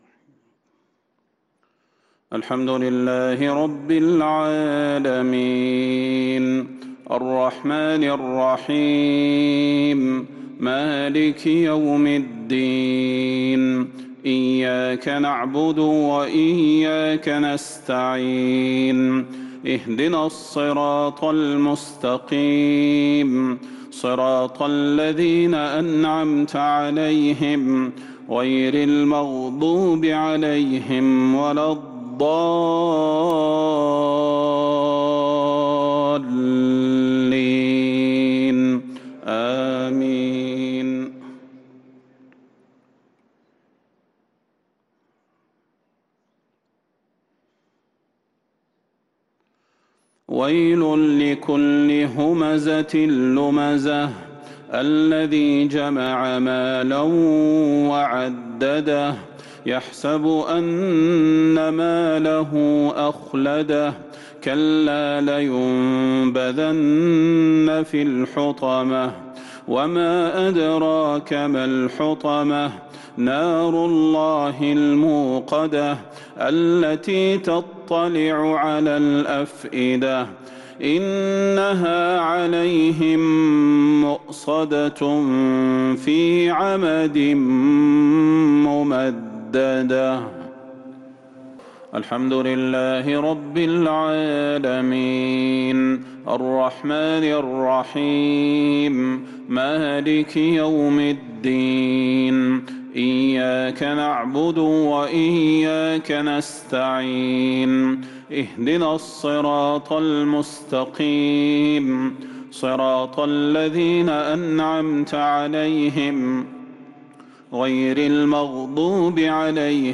صلاة المغرب للقارئ صلاح البدير 18 محرم 1443 هـ
تِلَاوَات الْحَرَمَيْن .